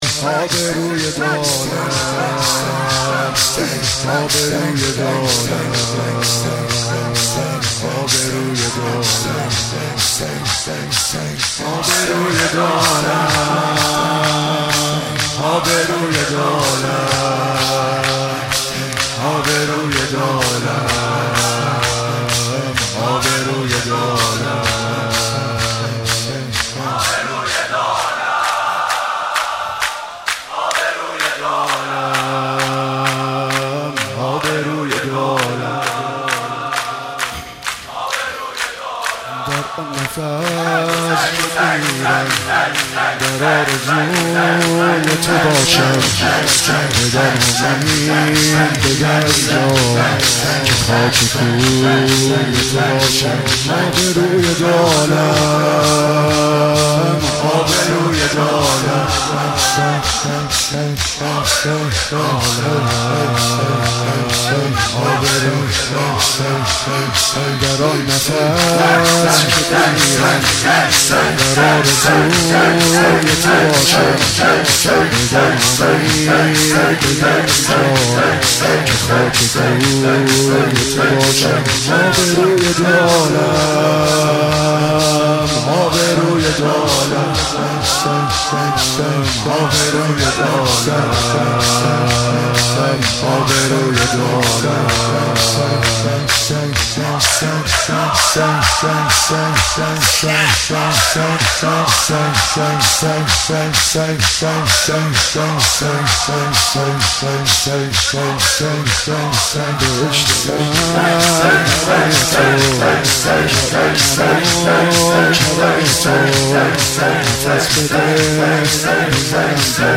مداحی حاج عبدالرضا هلالی در شب تاسوعای حسینی را بشنوید.